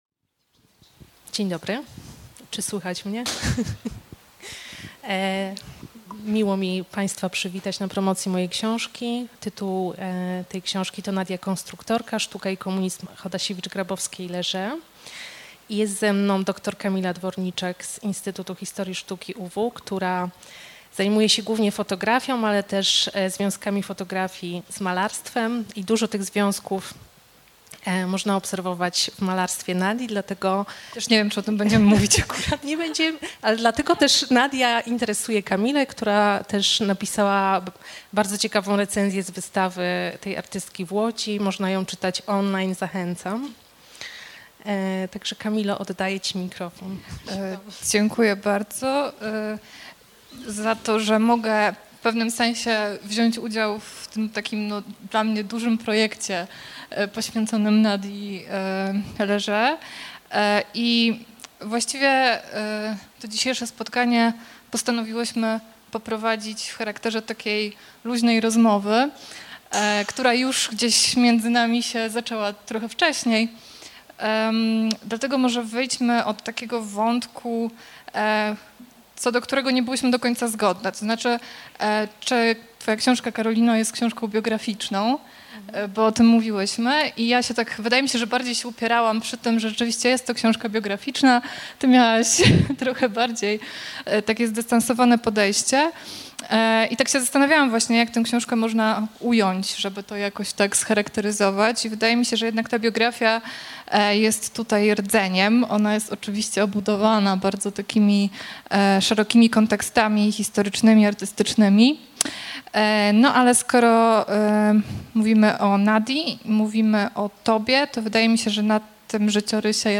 Play Nadia konstruktorka Promocja książki audio Data powstania